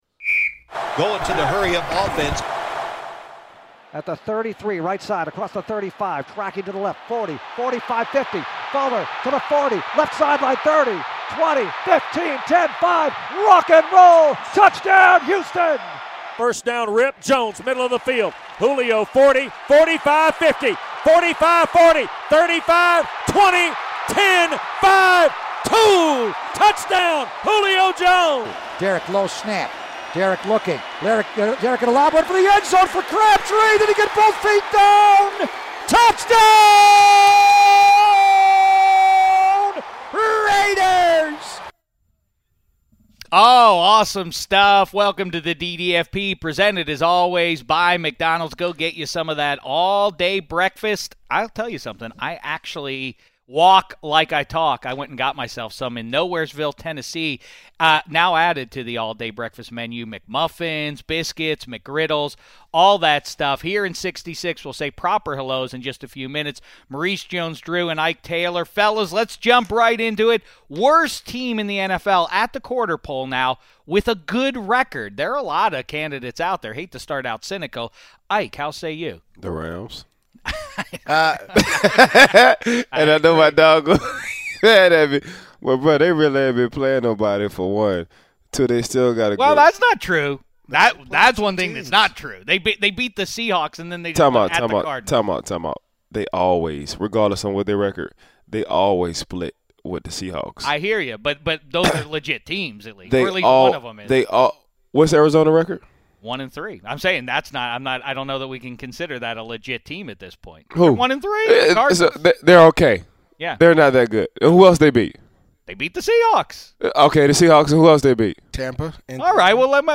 Shek is joined by Ike Taylor and Maurice Jones-Drew to discuss the best teams with bad records and the worst teams with good records so far this season. Then, the guys break down the best wins and worst losses from Week 4, highlighting the Steelers' win and the Lions' loss. Also, the guys analyze the upcoming matchup between notorious trash talkers, Josh Norman and Steve Smith Sr. Plus, as an audio exclusive, Shek interviews legendary filmmaker George Romero to talk about the newest episode of NFL Network's The Timeline called Night of the Living Steelers.